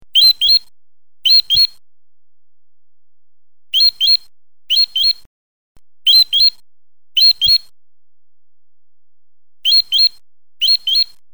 Die Bootsmannpfeife
Der  laute und schrille Klang übertönte bei Wind und Sturm das Knattern der Segel.
Durch die Stärke des Luftstroms und ändern der Finger-u. Handhaltung werden tiefe und hohe Töne erzeugt.
Pfeifensingnale als mp3 zum anhören.